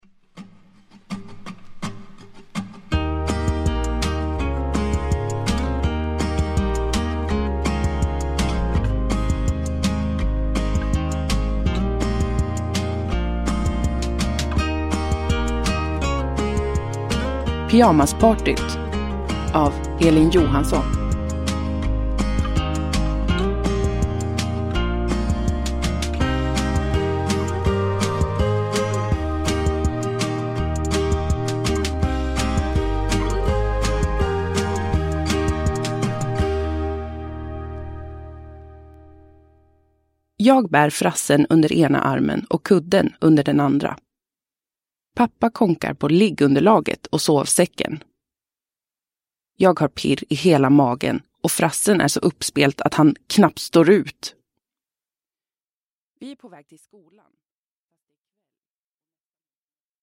Pyjamaspartyt – Ljudbok – Laddas ner